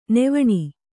♪ nevaṇi